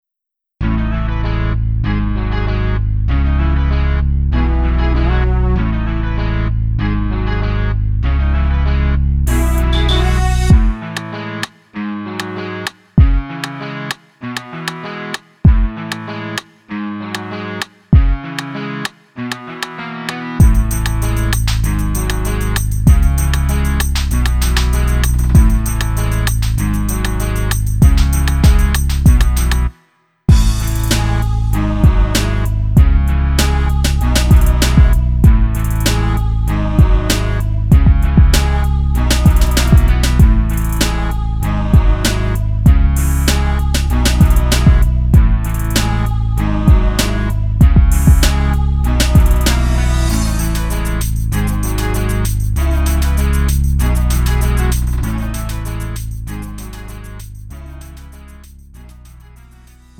음정 -1키 3:05
장르 구분 Lite MR